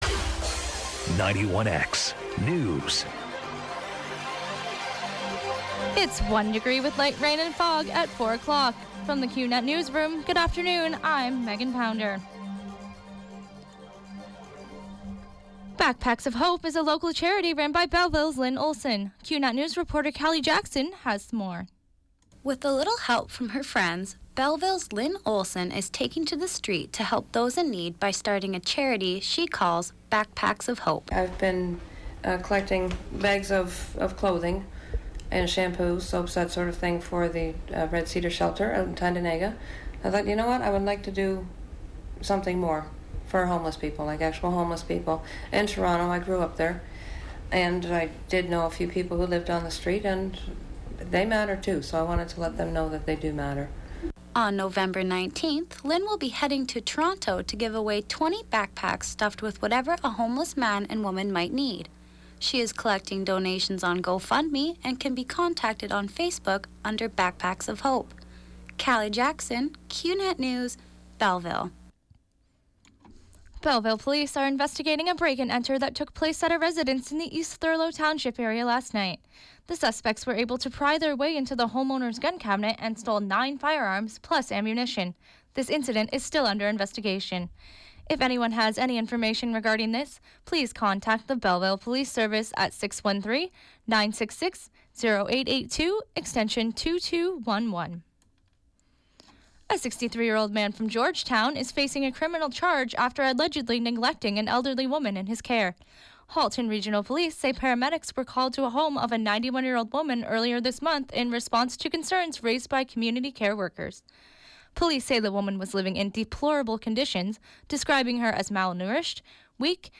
91X FM Newscast – Thursday, October 27, 2016, 4 p.m.